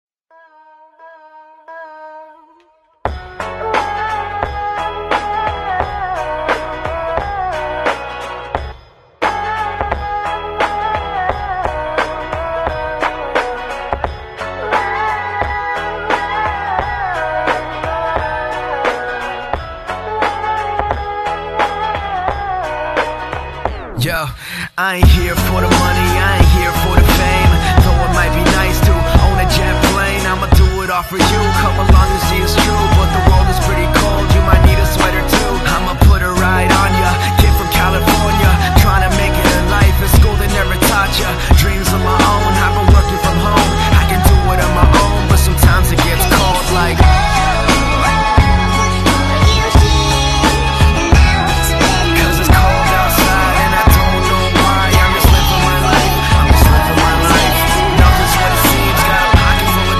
Güllegefahren mit einem John Deere